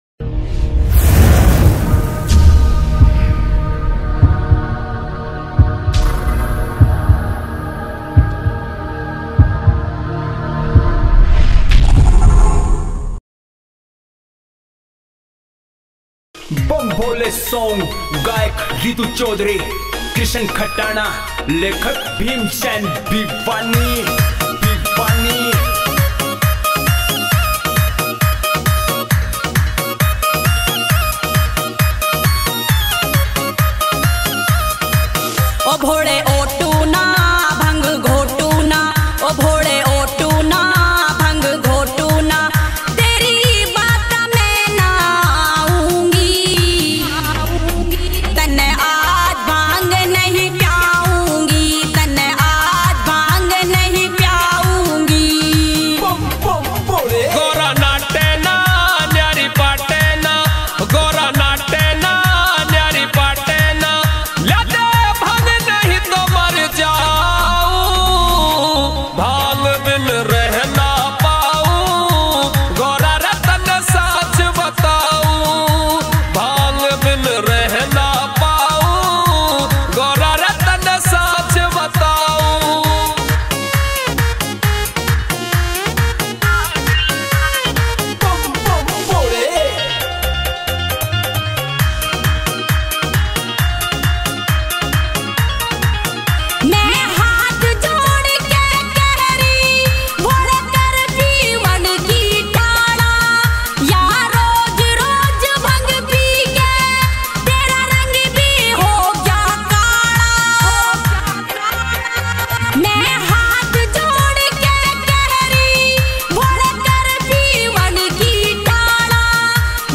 shiv dj bhajans remix